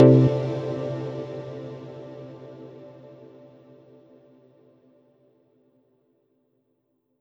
menu-play-click.wav